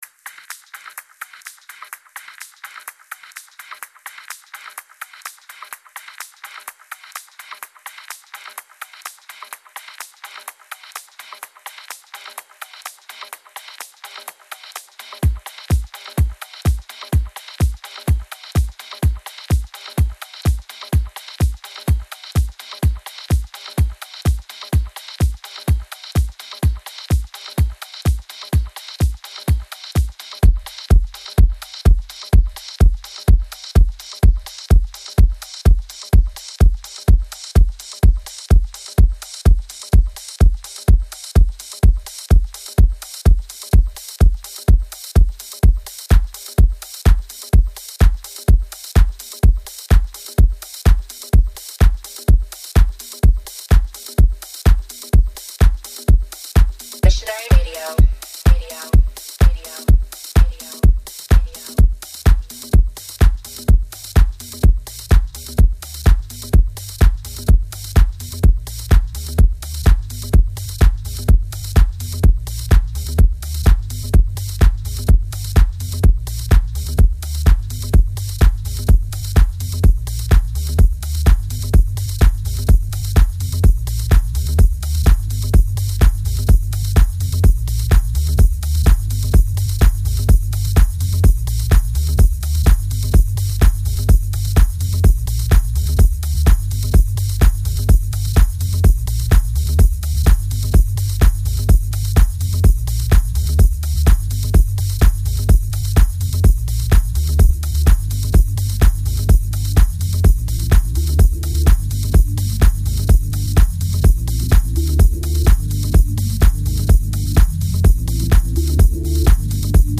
Online House, Tribal, Tech House, Trance and D&B.